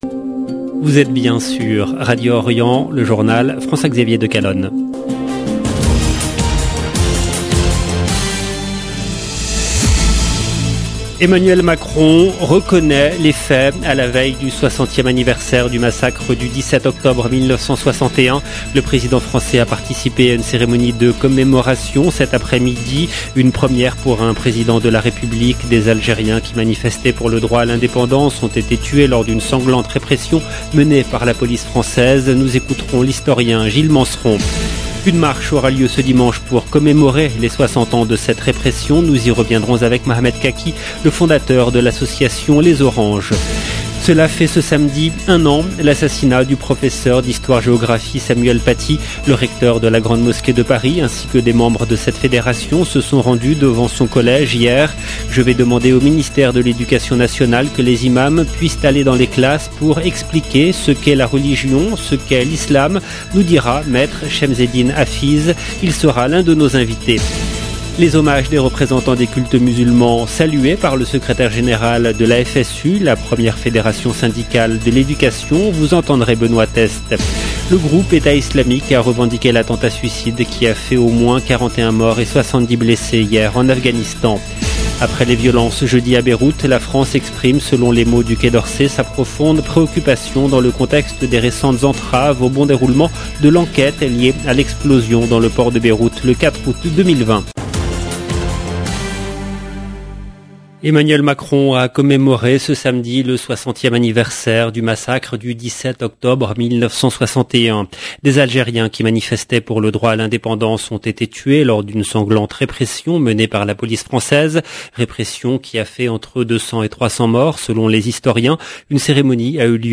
JOURNAL EN LANGUE FRANCAISE